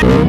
PLAY hmmm sound effect free download